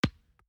ball_2.mp3